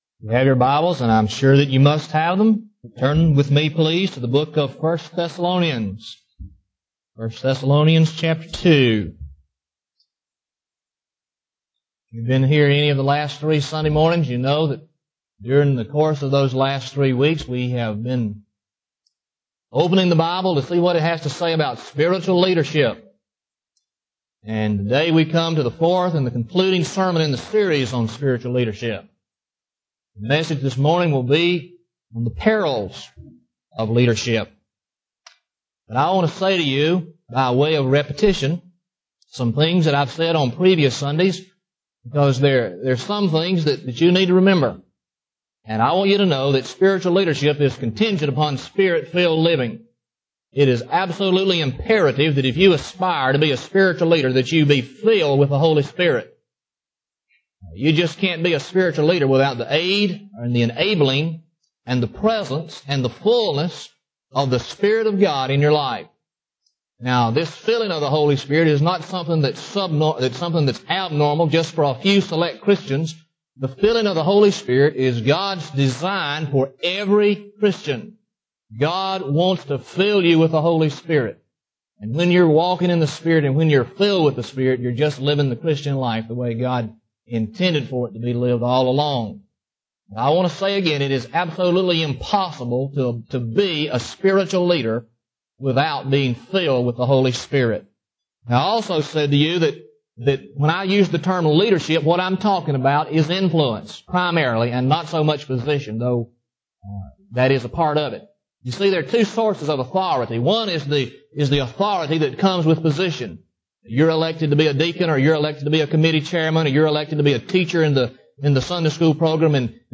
Sermon Series: Spiritual Leadership #4